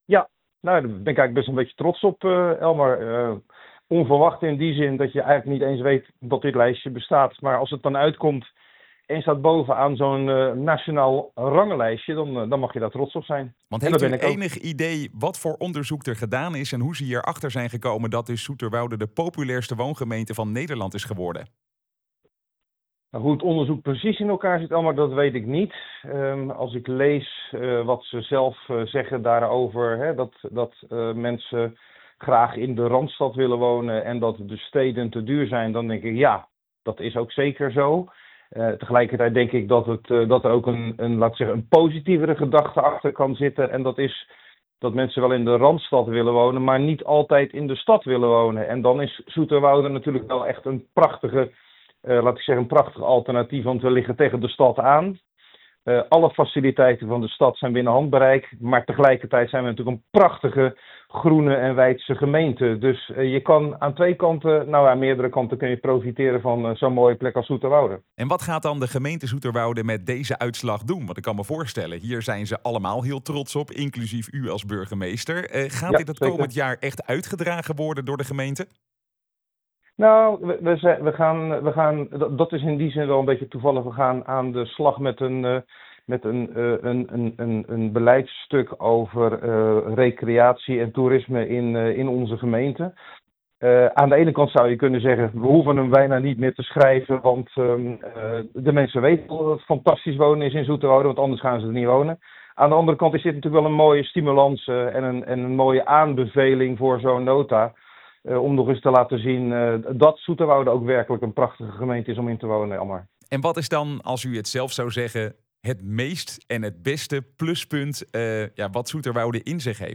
in gesprek met burgemeester Fred van Trigt.